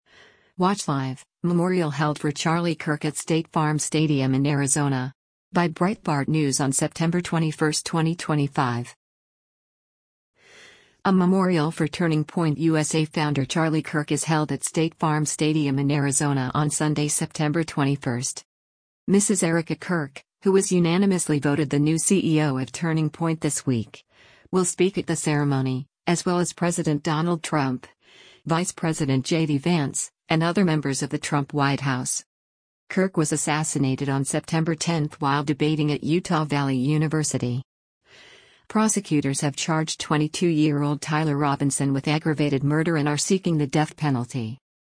A memorial for Turning Point USA founder Charlie Kirk is held at State Farm Stadium in Arizona on Sunday, September 21.